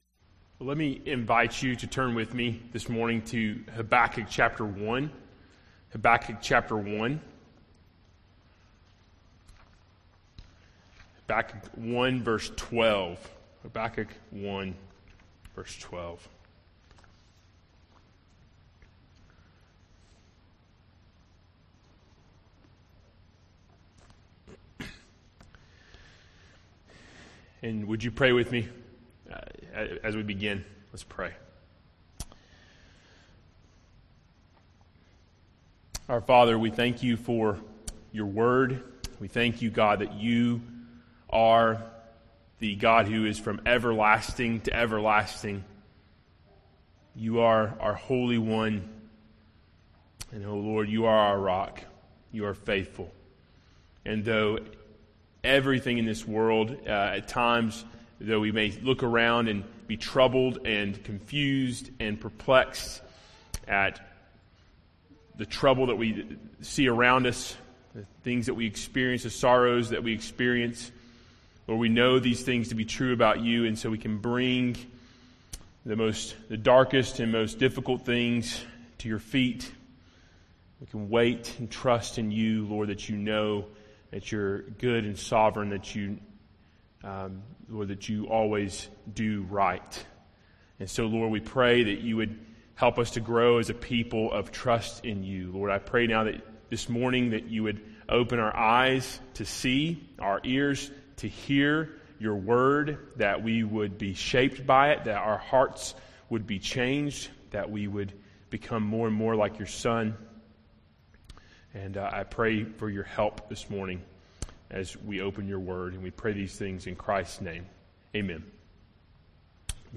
expository preaching
Sermon Audio